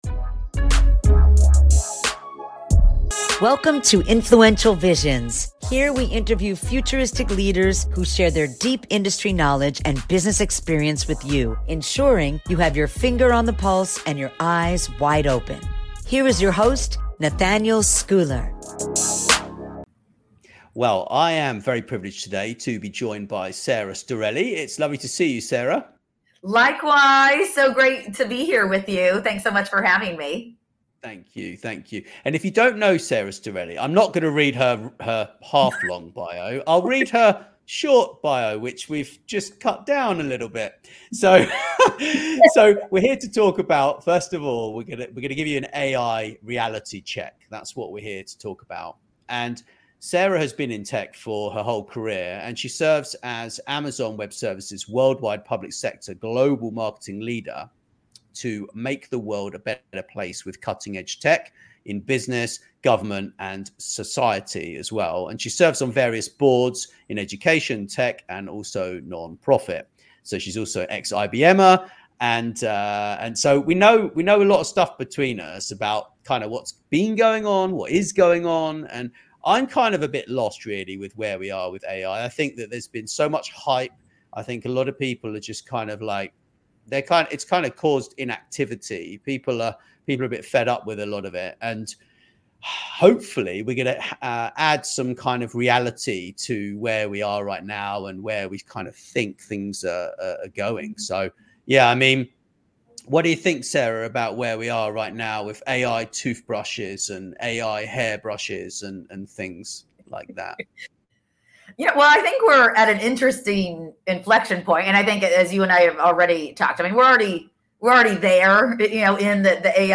This week’s interview on AI